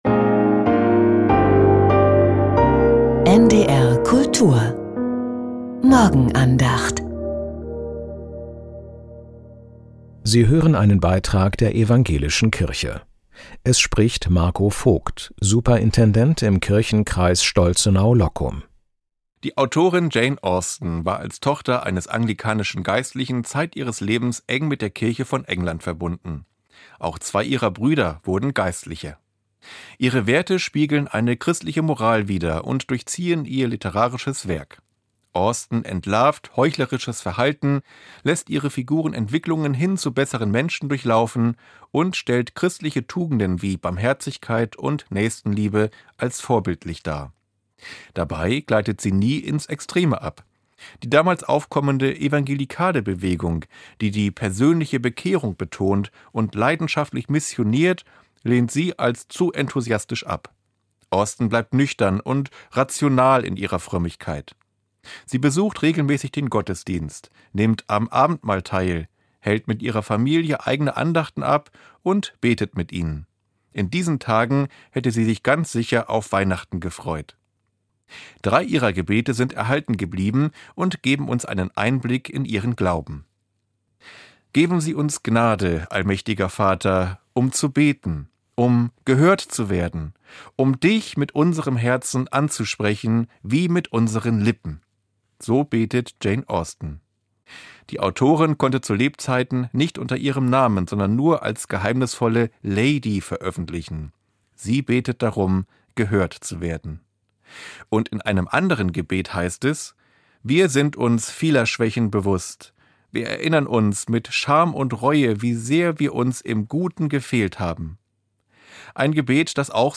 Jane Austens persönlicher Glaube ~ Die Morgenandacht bei NDR Kultur Podcast